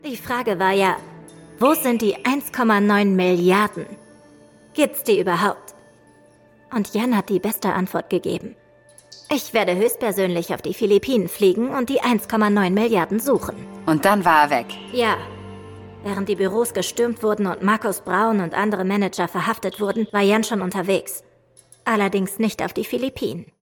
Die größten Fälle des BND | Hörspiel